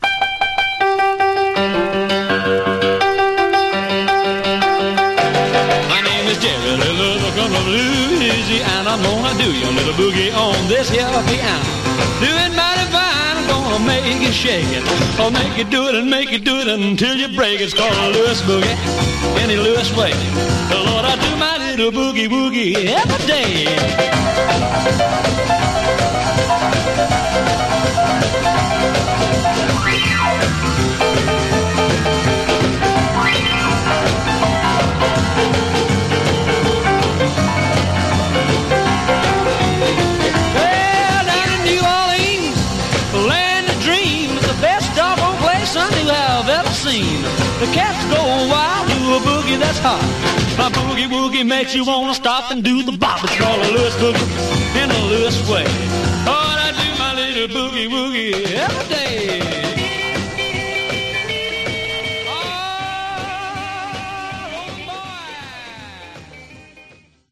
Genre: Rockabilly/Retro